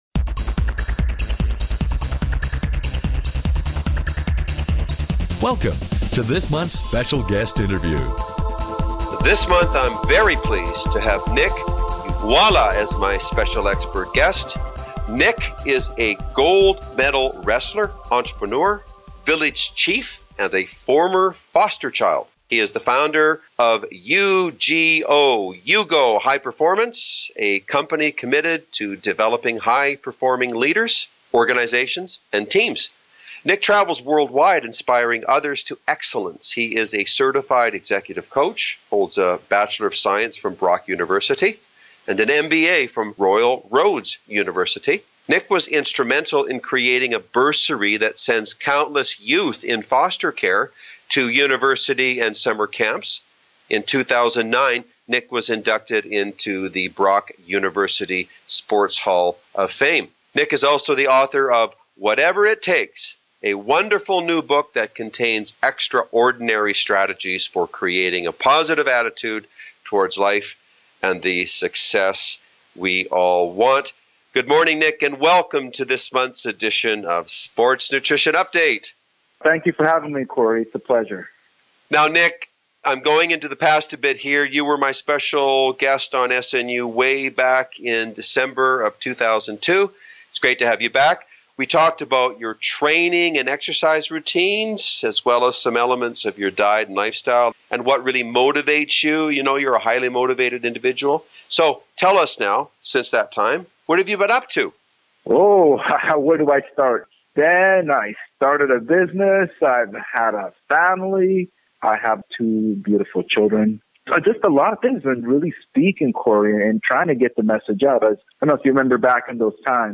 Special Guest Interview Volume 14 Number 1 V14N1c